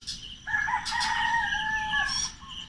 A peaceful start to the day, with the possible exception of the cockerels 🐔
CockeralLouder2019.mp3